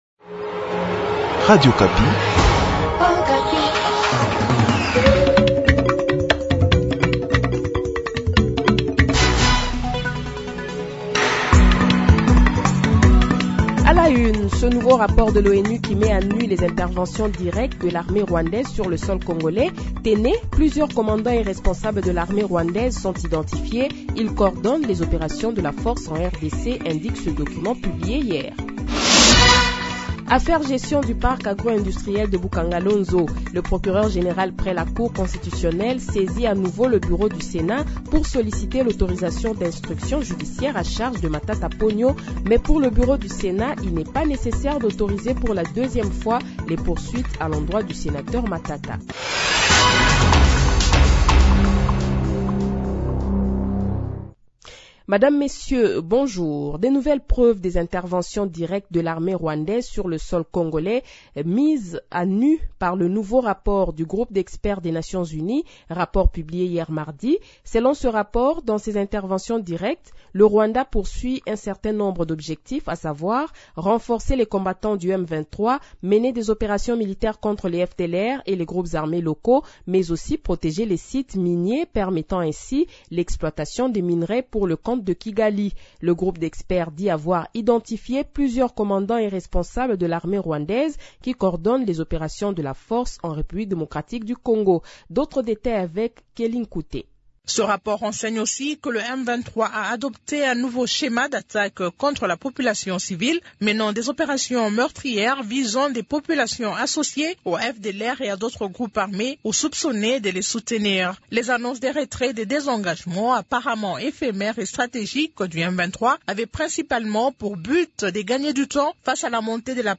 Le Journal de 7h, 21 Juin 2023 :